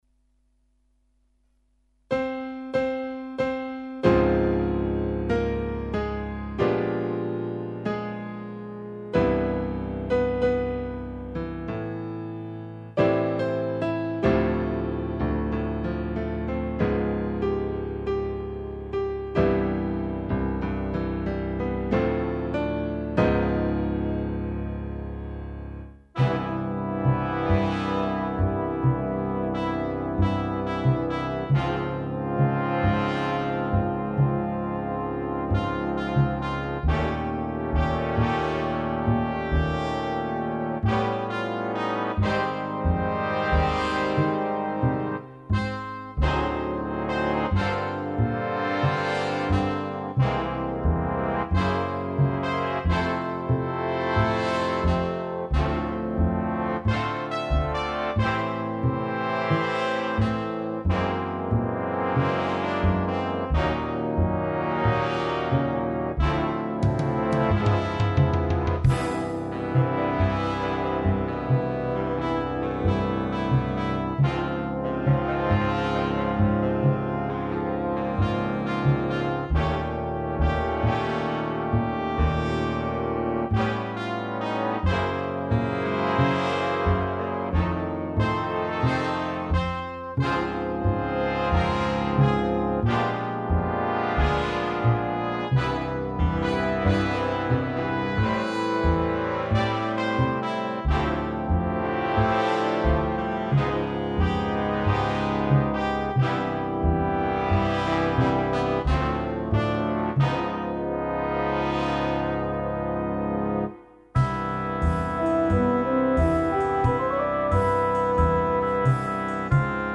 Piano / Guitar / Bass / Drums